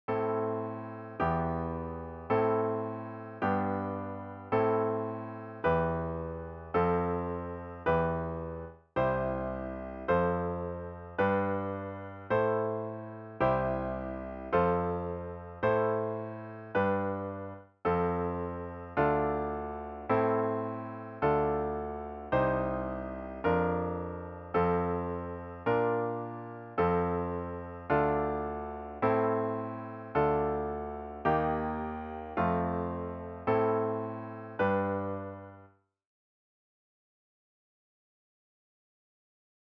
The examples are in C major/A minor, but are of course transposable to any key.
A new browser window will open, and you’ll hear each progression in basic long-tones.
Listen VERSE:  Am  Em  Am  G  Am  Em  F  Em || CHORUS:  C  F  G  Am  C  F  Am  G  || BRIDGE:  F  Dm  Am  Dm  Cm  Bb/F  F  Bb  F  Dm  Am  Dm  Eb  F  Am  G